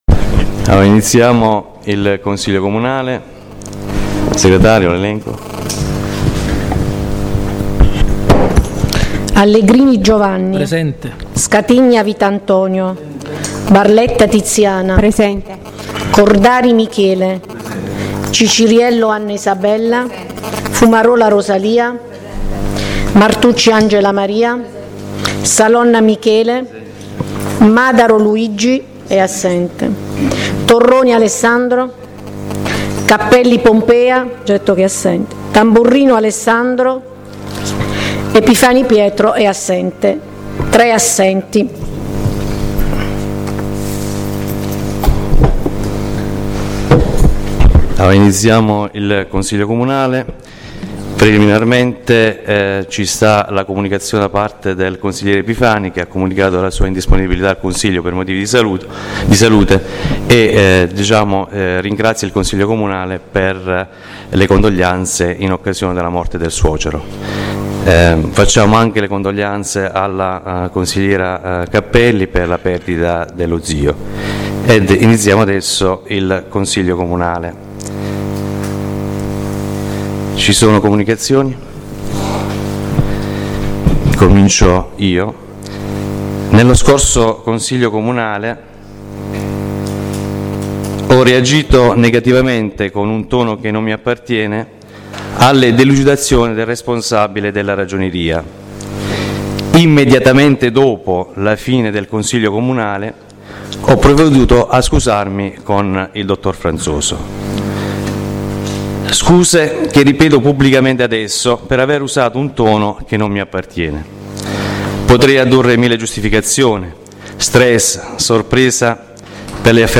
La registrazione audio del Consiglio Comunale di San Michele Salentino del 08/02/2019